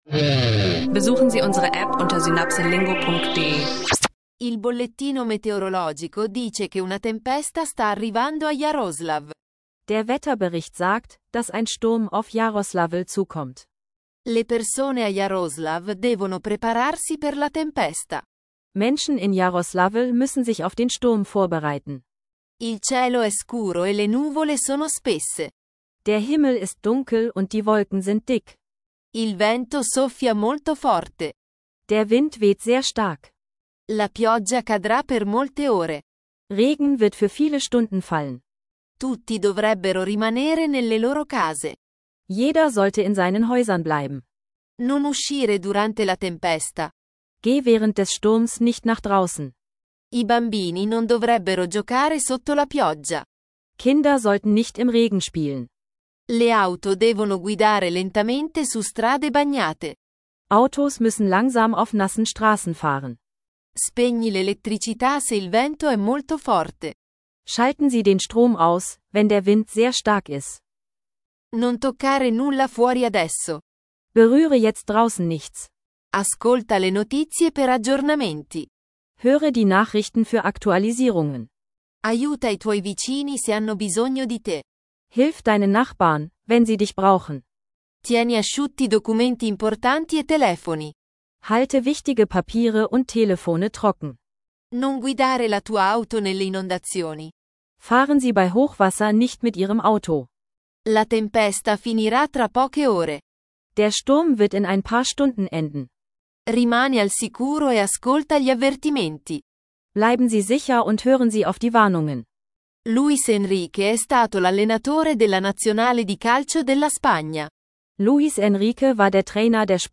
In dieser Folge tauchst du ein in realistische Dialoge rund um eine Sturmwarnung in Jaroslawl und die Controversia um Luis Enrique, ideal für Italienisch Lernen, Italienisch lernen Podcast, und Alltagspraxis - perfekt für Anfänger und Fortgeschrittene.